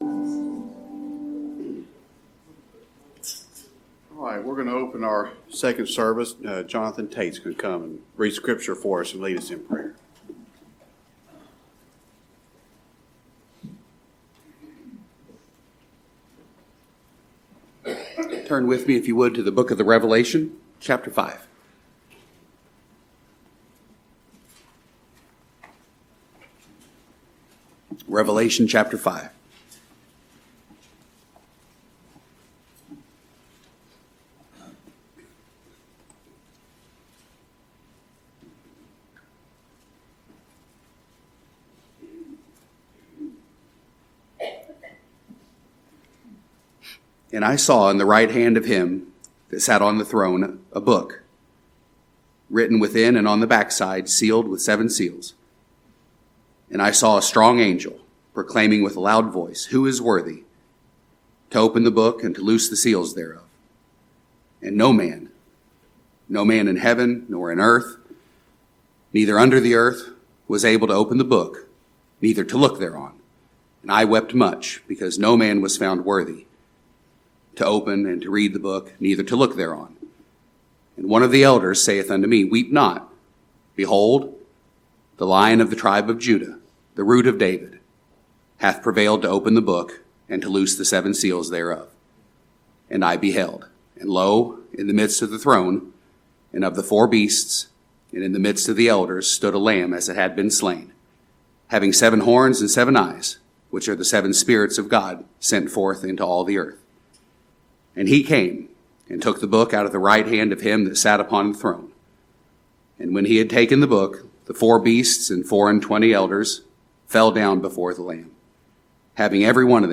I Am In Remission Forever | SermonAudio Broadcaster is Live View the Live Stream Share this sermon Disabled by adblocker Copy URL Copied!